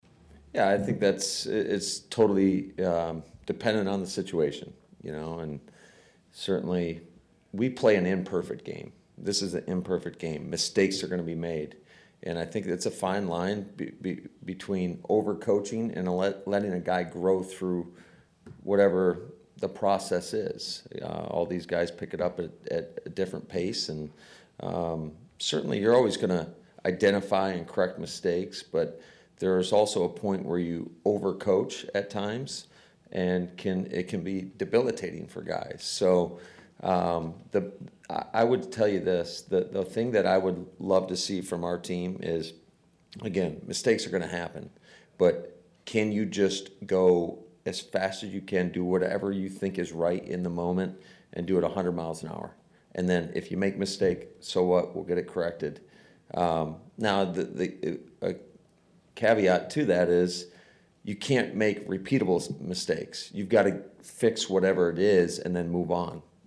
In his pre-practice media session, Matt LaFleur had high praise for Jets receiver Corey Dillon.